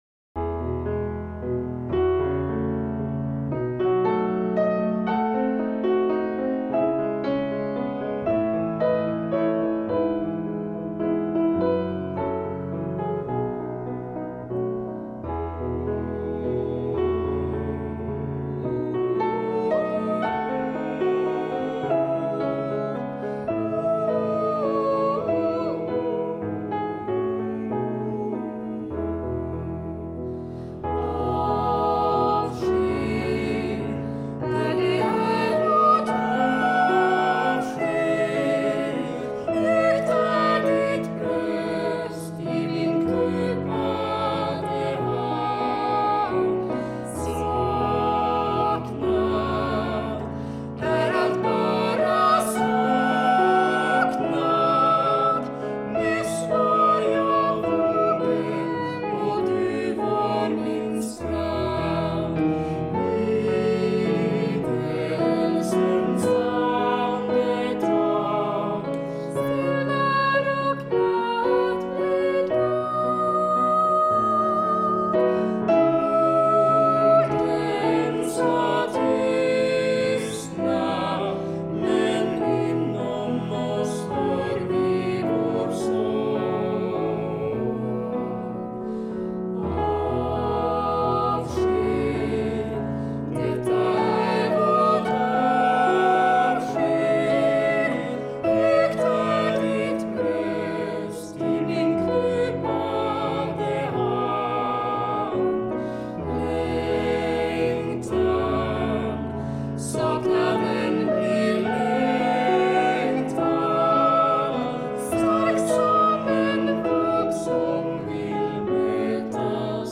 sång